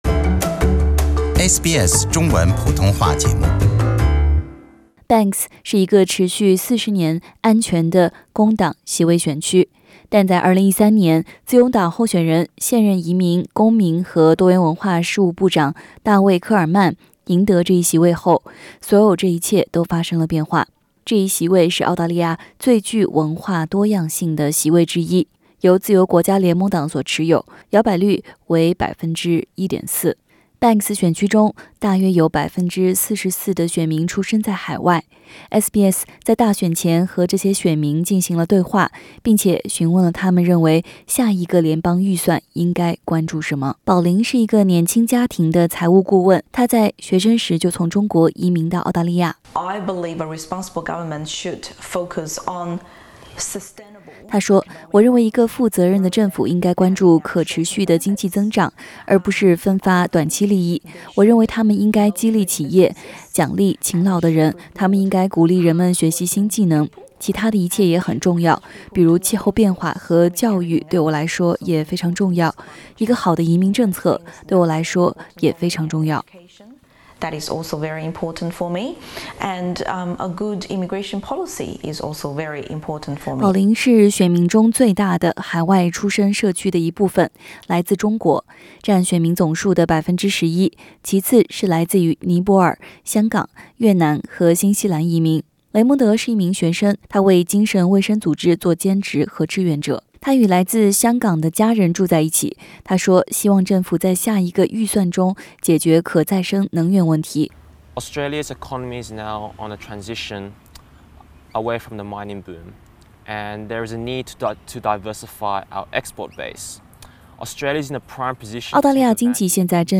我们向全国最摇摆选区的选民们了解了，他们心中能让选举获胜的预算案是什么样子的。 SBS 新闻和与选区BANKS的选民进行了交谈，了解哪些投资和储蓄措施对他们来说更加重要。